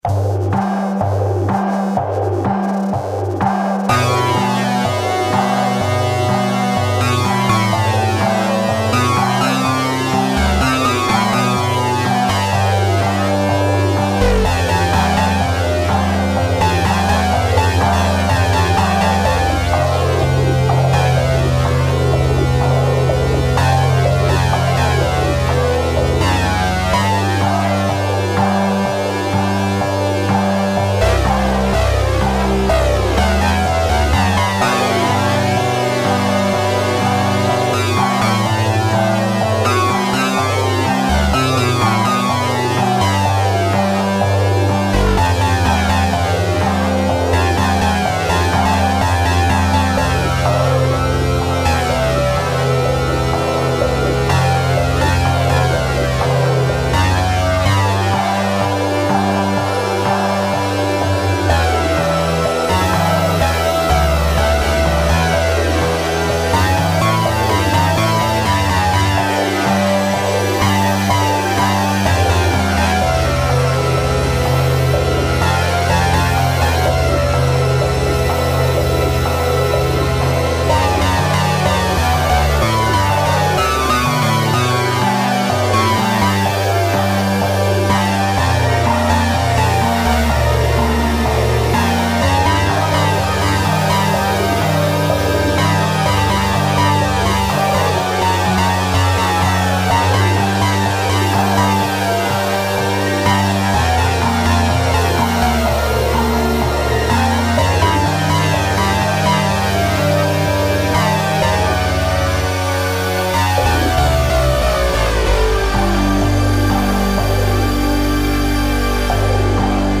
Elka Synthex + Kawai k5000s